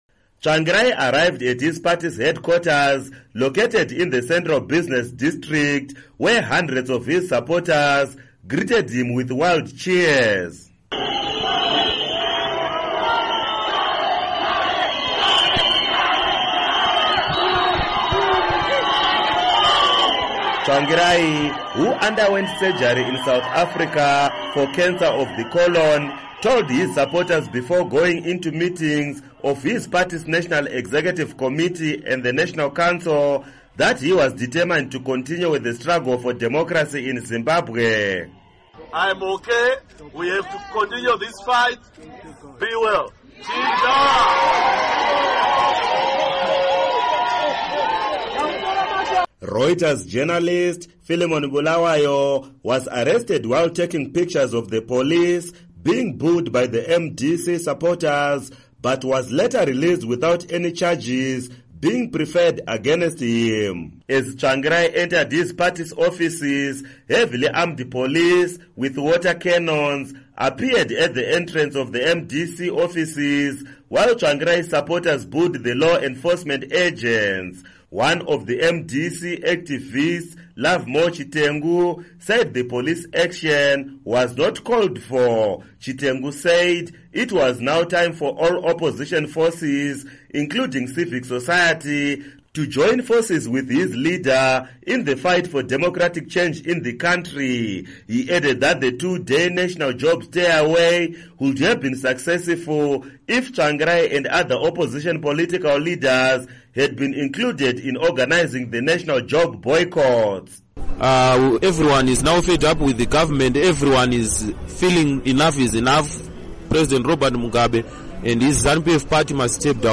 Report On Tsvangirai
And MDC- T leader Morgan Tsvangirai speaks in public for the first time after telling Zimbabweans that he has cancer of the colon.